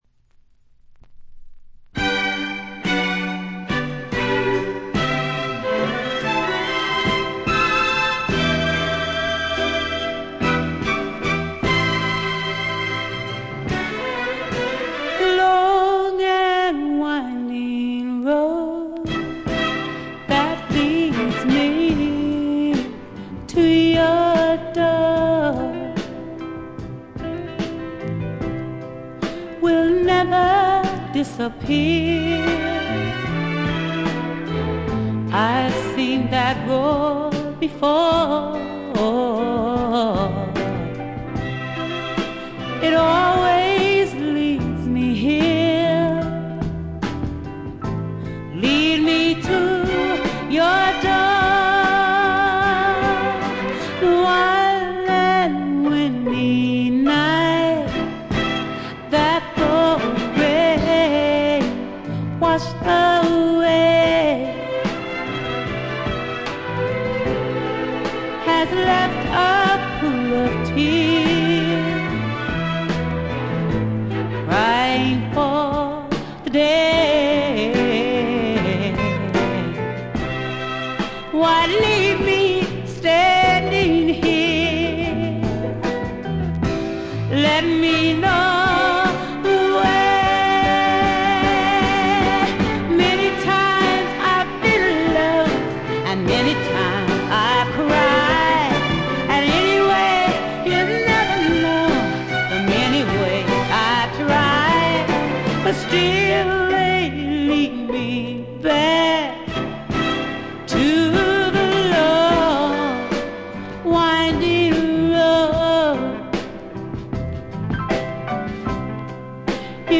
Piano, Organ